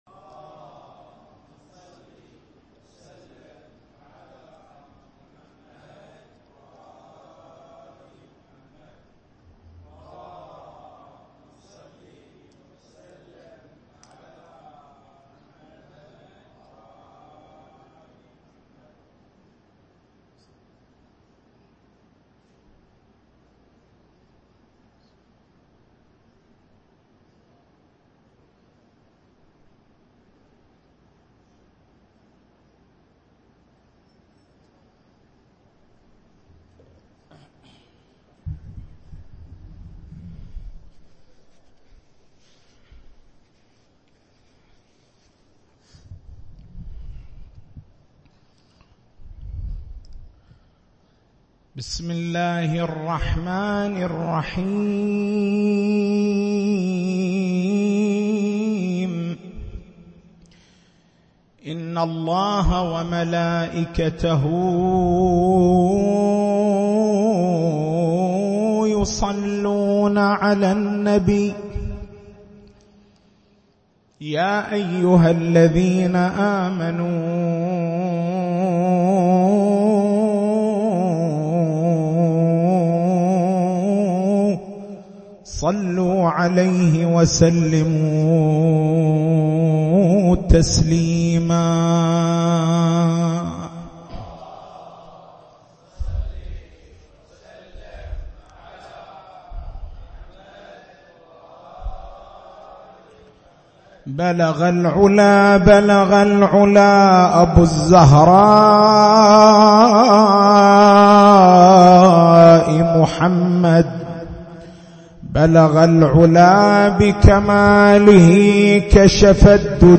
ألقى سماحة السيّد كلمة مختصرة